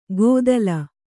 ♪ gōdalu